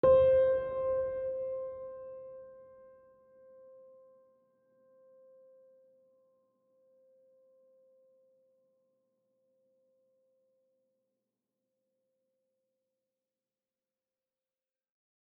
piano-sounds-dev
c4.mp3